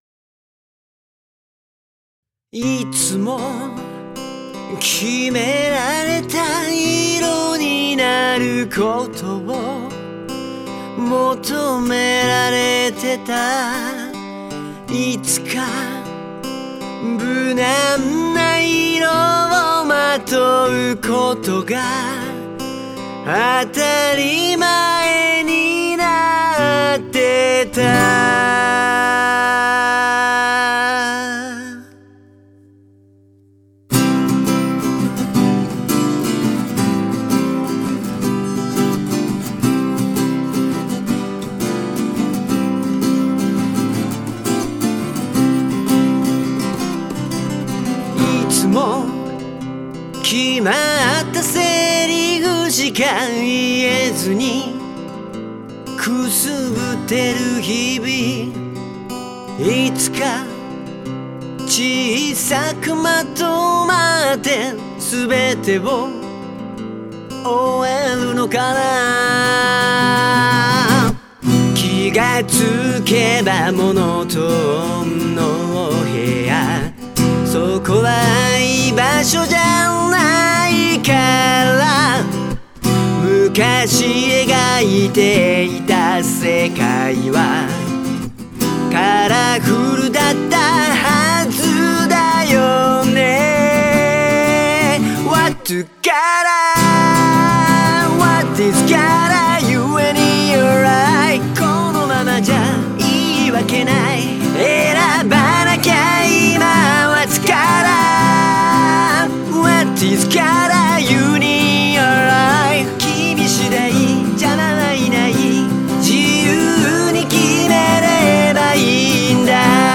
ゲスト女性ボーカル
SAXプレーヤー
全曲オリジナルで綴る初のバンド構成でのステージです。
【試聴音源:POPS】